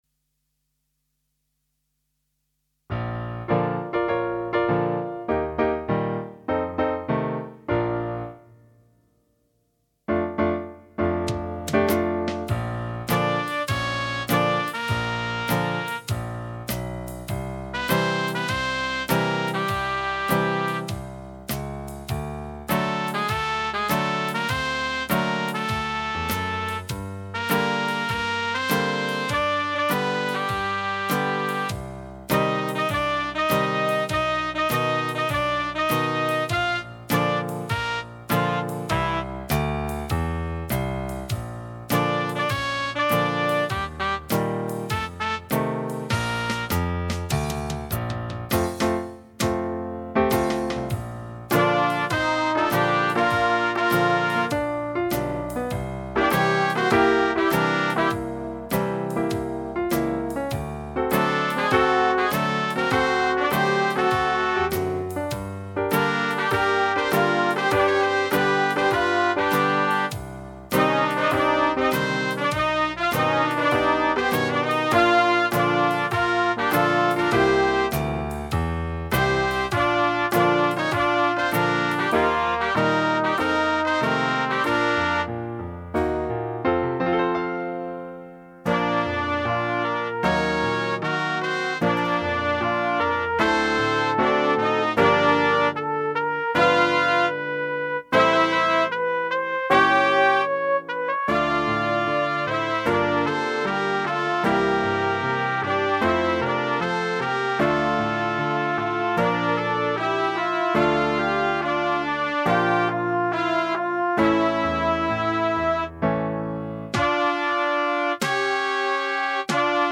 minus Bass